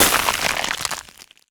ice_spell_freeze_frost_05.wav